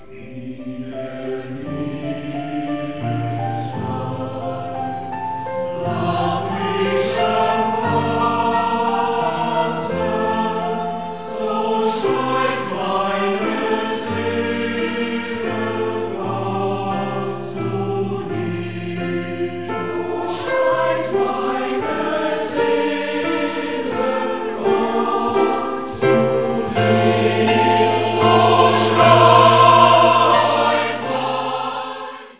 Die Chöre der Germania Glattbach, bestehend aus dem Frauenchor, dem Männerchor sowie dem Jungen Chor “Gaudium Cantandi” im Jahr des 125- jährigen Vereinsjubiläums (1998) vor der Stiftskirche in Aschaffenburg